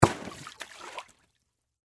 Звук баскетбольного мяча упавшего в лужу воды